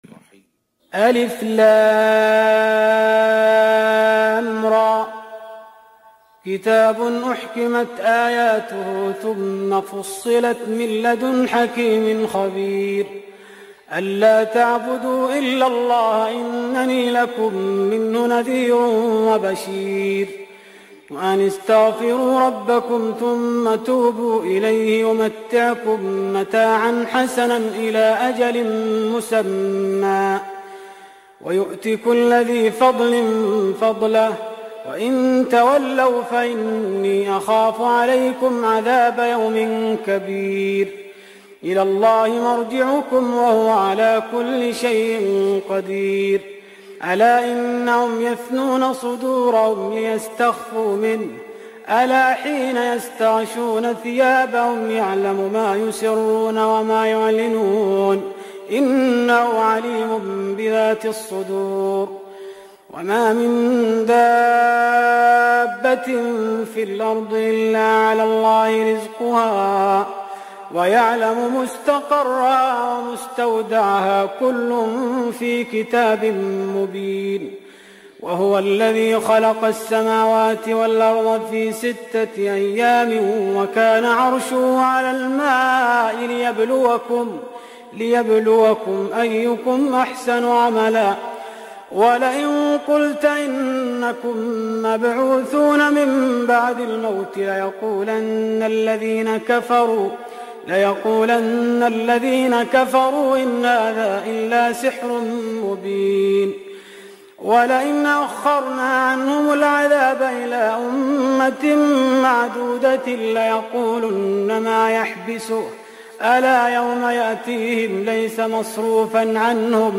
تهجد رمضان 1417هـ سورة هود Tahajjud Ramadan 1417H from Surah Hud > تراويح الحرم النبوي عام 1417 🕌 > التراويح - تلاوات الحرمين